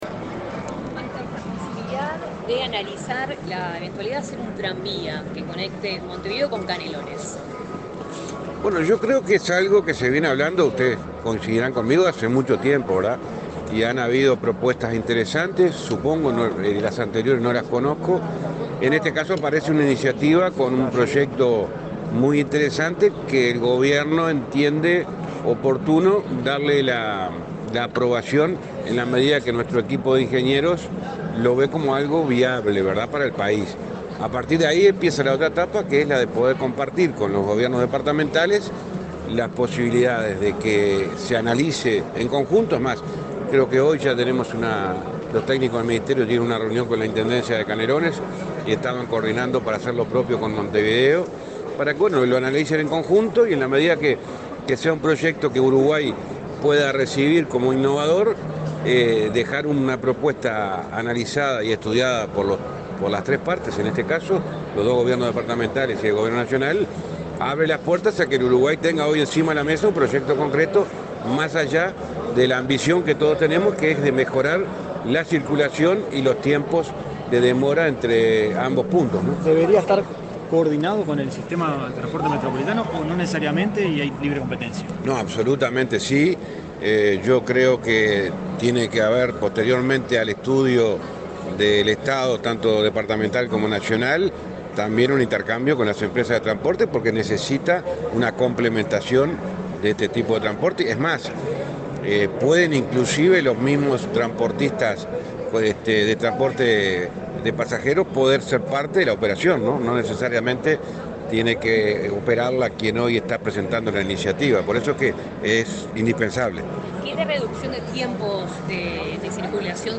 Declaraciones del ministro de Transporte, José Luis Falero
Declaraciones del ministro de Transporte, José Luis Falero 15/06/2023 Compartir Facebook X Copiar enlace WhatsApp LinkedIn El ministro de Transporte, José Luis Falero, dialogó con la prensa luego de participar en la inauguración del Polo Logístico Industrial Ciudad del Plata, en el departamento de San José.